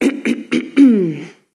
voz nș 0162